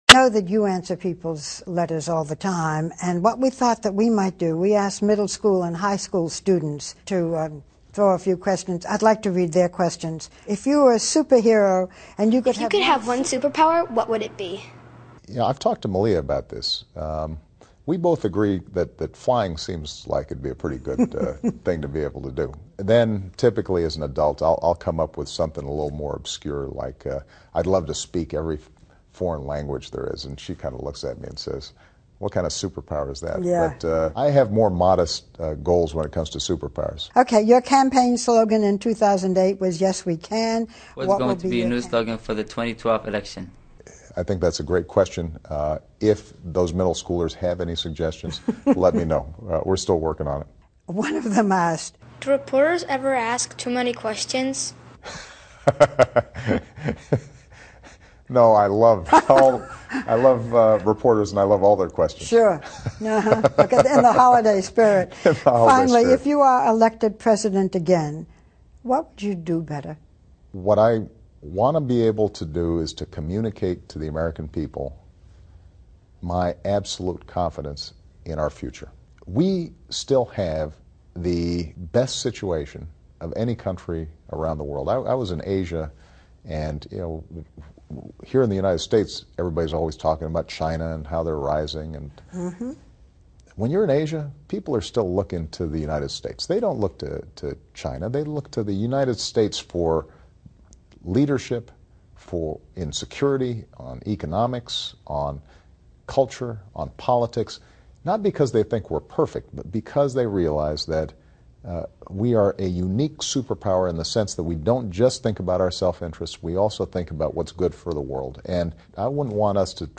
访谈录 2012-01-02&01-04 奥巴马答小孩问 听力文件下载—在线英语听力室